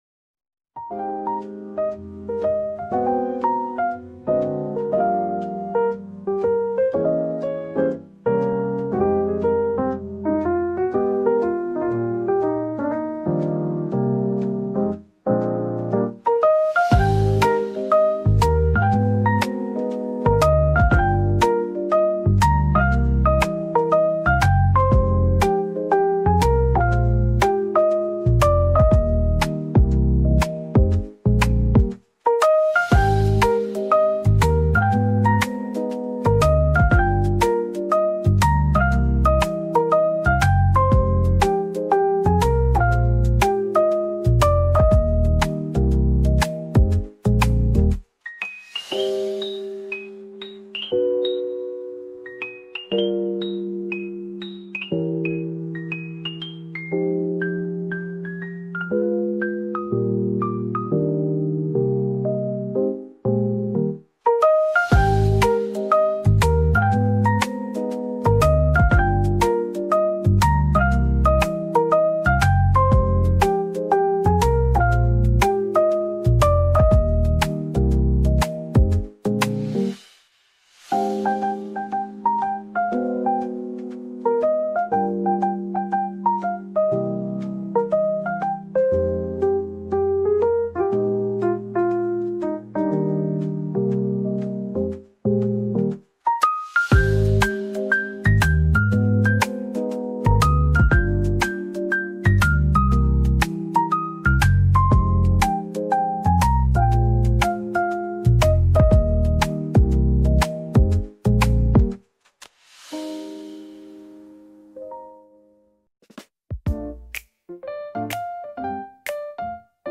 lo-fi music